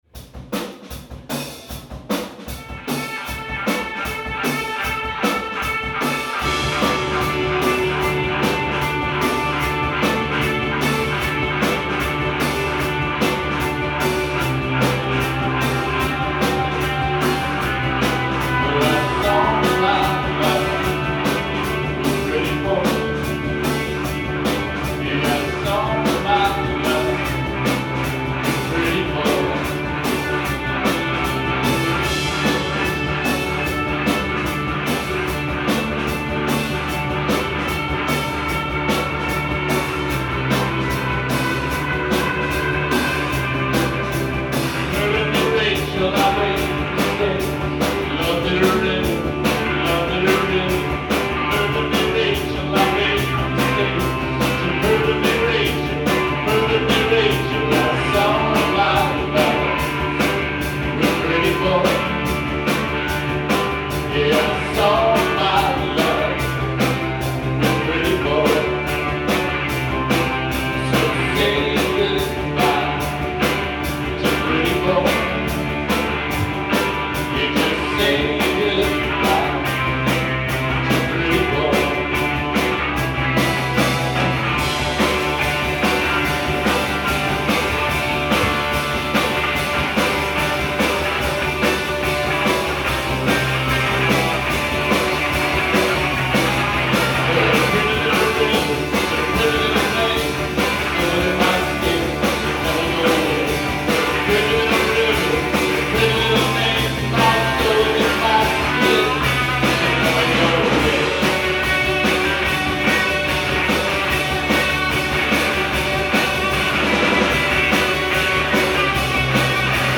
live at TT the Bears